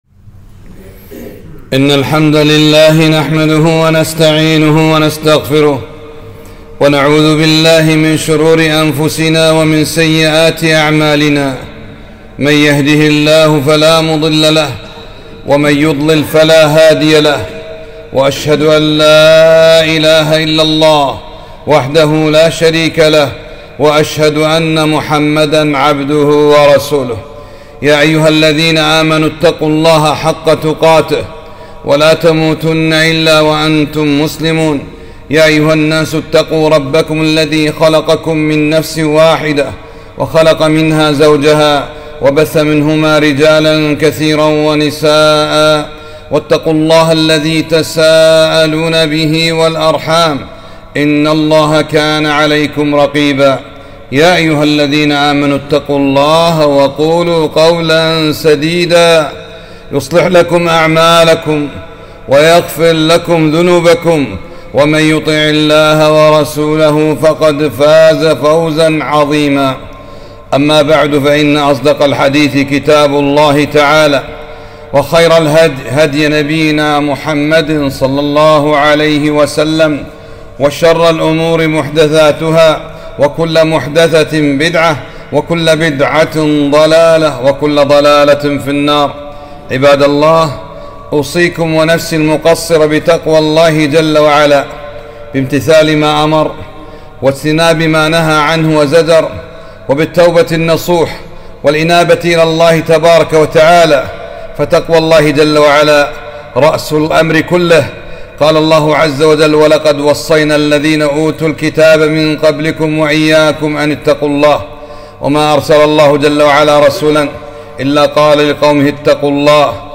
خطبة - لا تغتر بأعمالك ياعبد الله